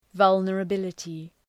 Προφορά
{,vʌlnərə’bılətı}